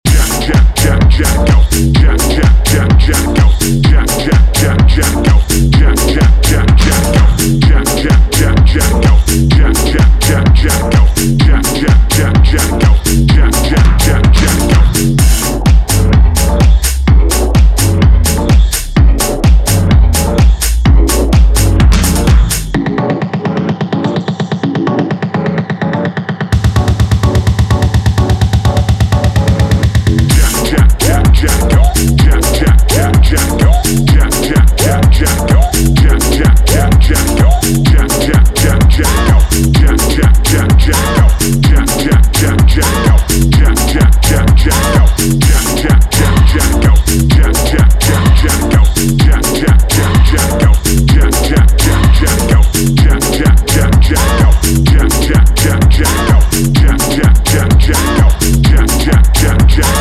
長いディスコ/ハウス史への眼差しを感じさせる楽曲を展開